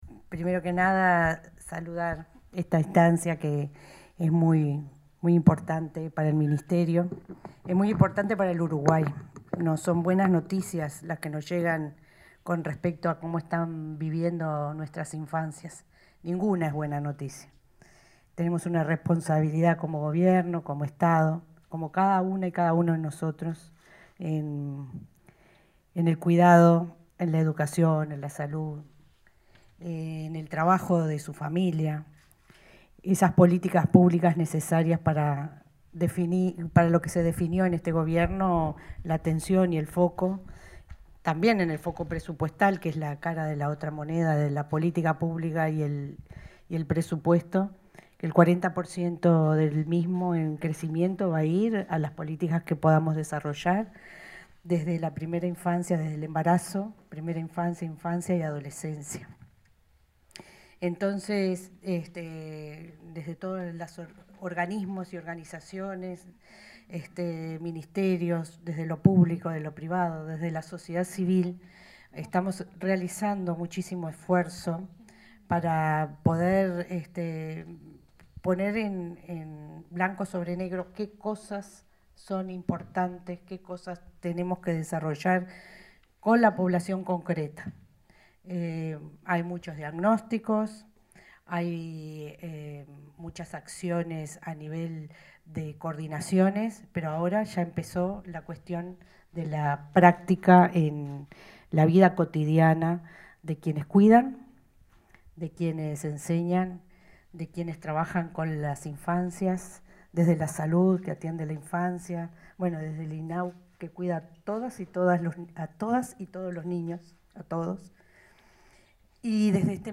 Palabras de la ministra interina de Educación y el director nacional de Desarrollo Social
La ministra interina de Educación y Cultura, Gabriela Verde, y el director nacional de Desarrollo Social, Nicolás Lasa, disertaron en la presentación